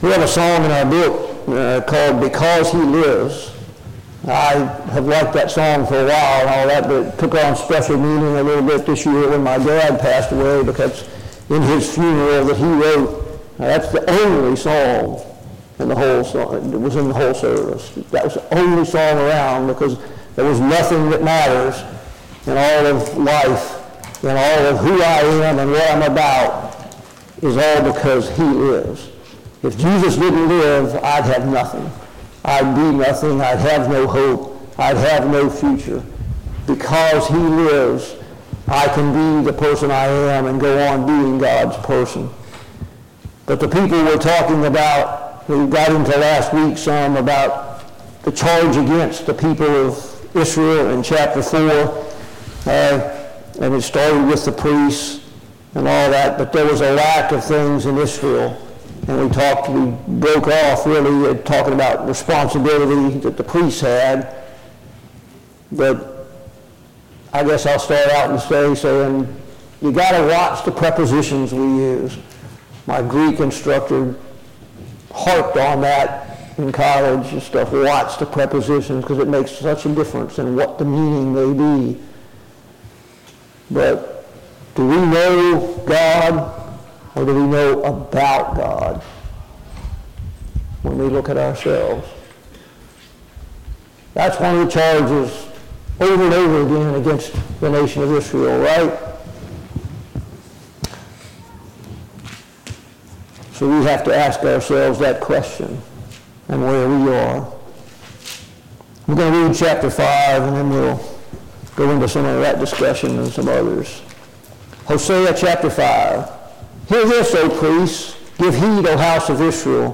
Study on the Minor Prophets Passage: Hosea 5:1-12 Service Type: Sunday Morning Bible Class « 4.